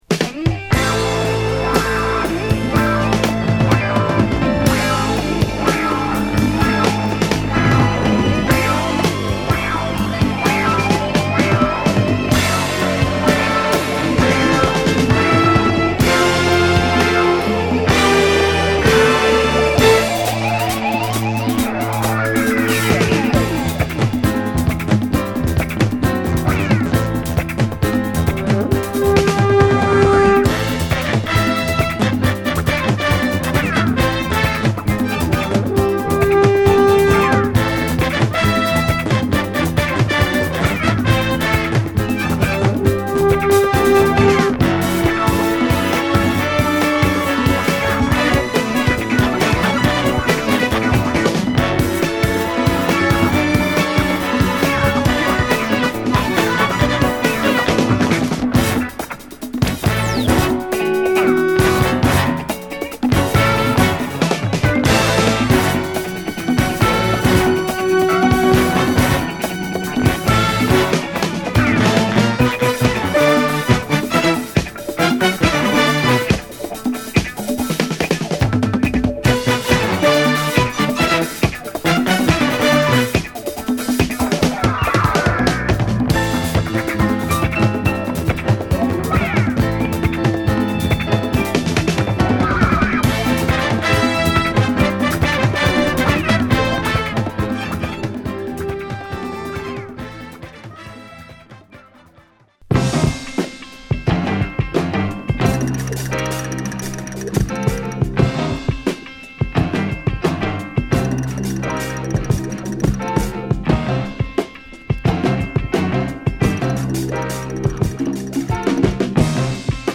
ホーンやクラヴィにシンセが堪らなくカッコ良い！！